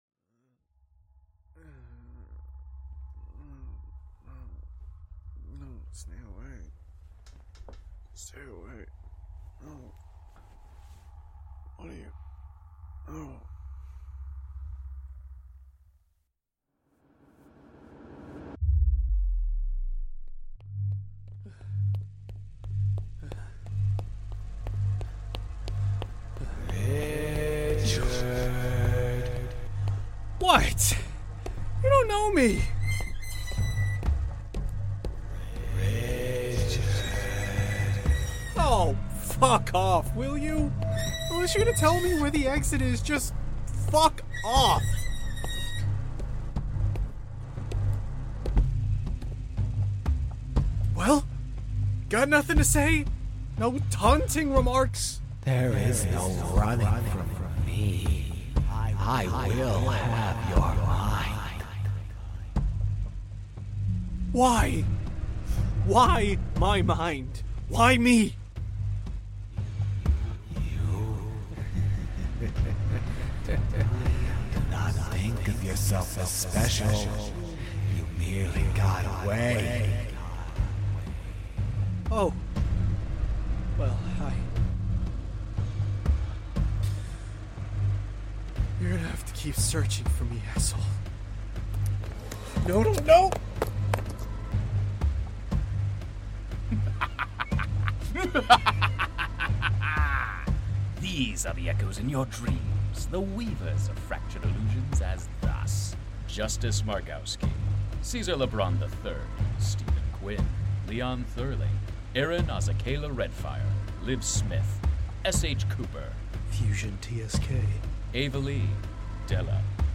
Play Rate Apps Listened List Bookmark Share Get this podcast via API From The Podcast Whispers From the Void Whispers from the Void is an immersive audio drama that plunges listeners into a world where the boundaries between reality and the supernatural are blurred.